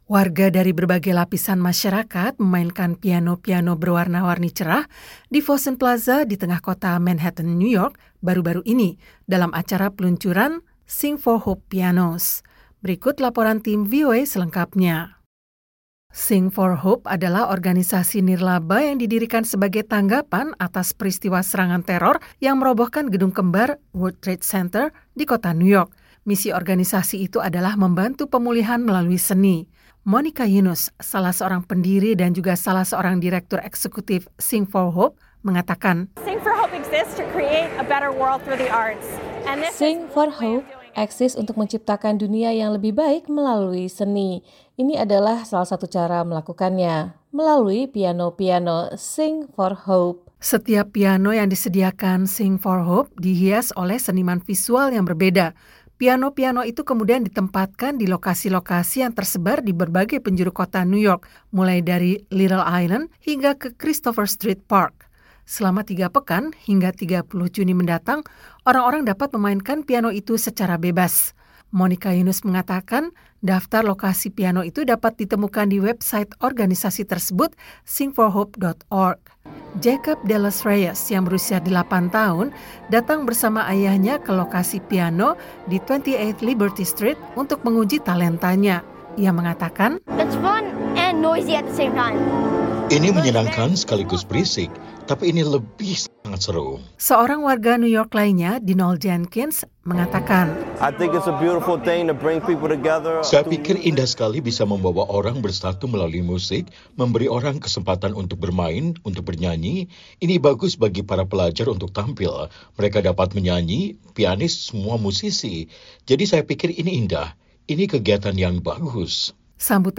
Warga dari berbagai lapisan masyarakat memainkan piano-piano berwarna-warni cerah di Fosun Plaza di tengah kota Manhattan, New York, baru-baru ini, dalam acara peluncuran "Sing for Hope Pianos".